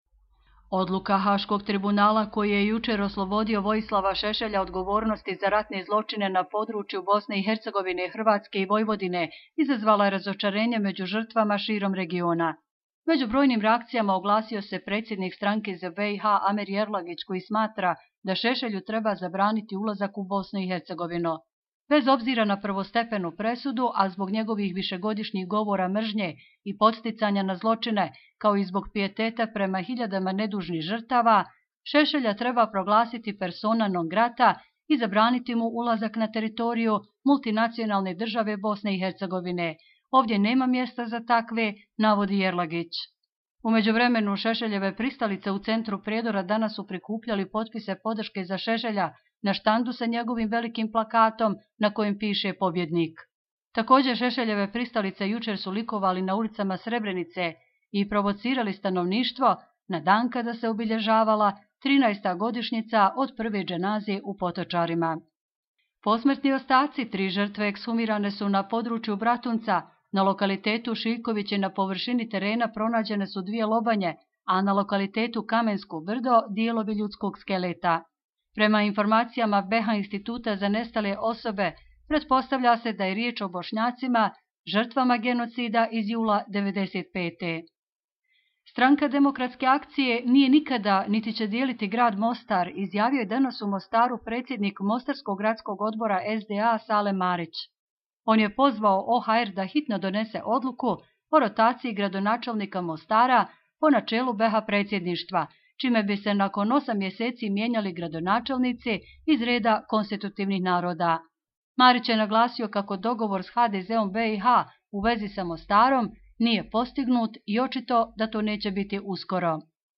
Audio izvještaji